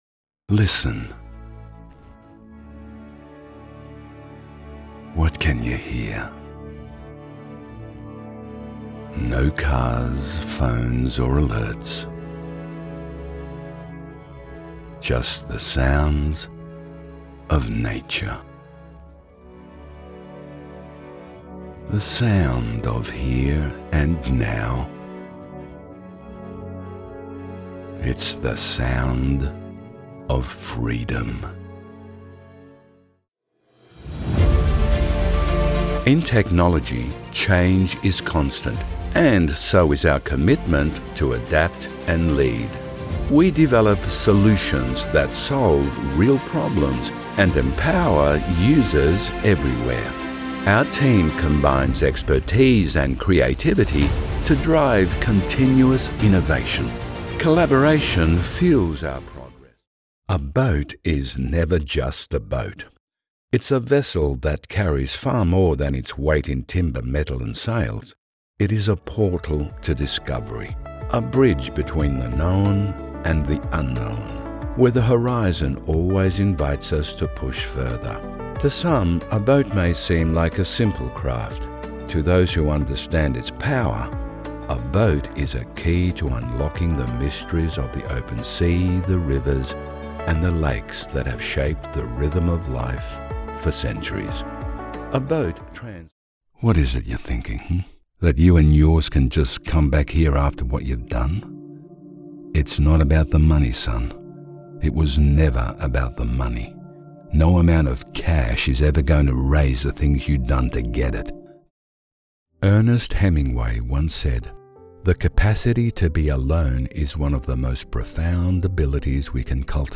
Male Voice Over Artists Actors & Talent for Hire Online
Older Sound (50+)